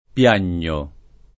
Piagno [ p L# n’n’o ]